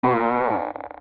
fart1.wav